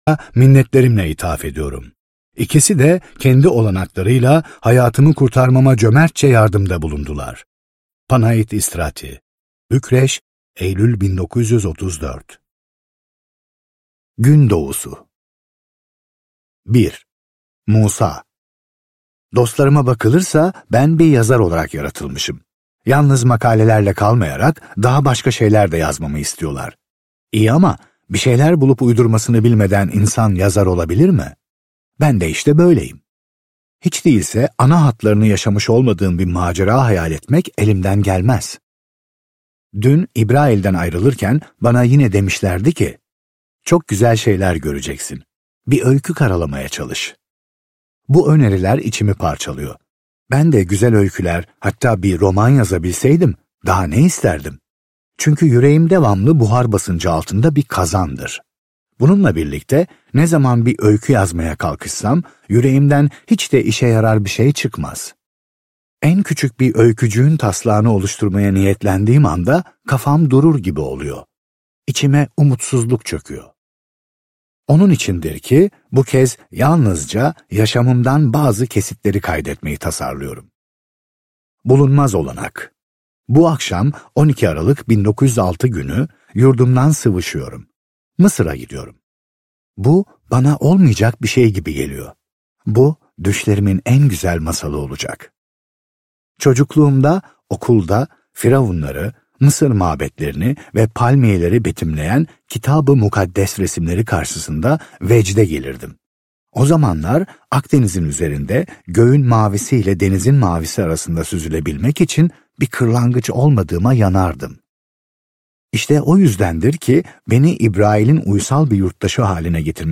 Akdeniz - Seslenen Kitap